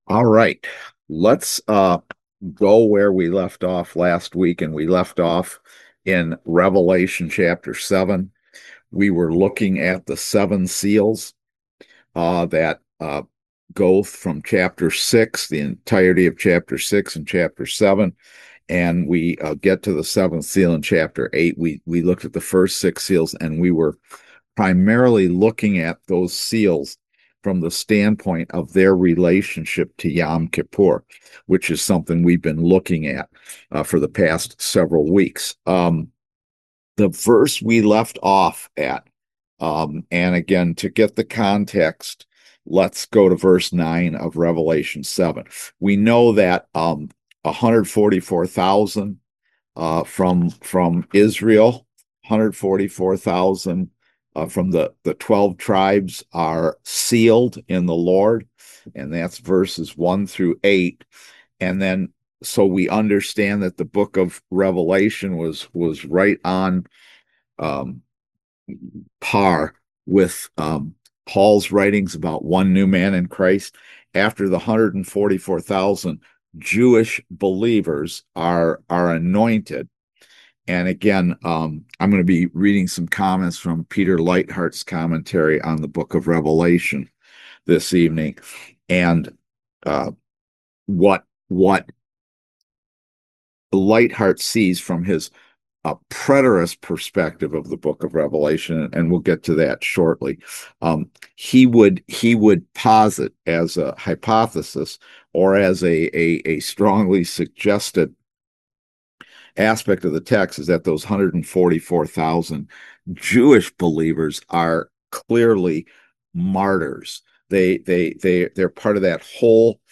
Series: Eschatology in Daniel and Revelation Service Type: Kingdom Education Class Download Files Notes Notes Notes Notes Notes Notes « Why Jesus?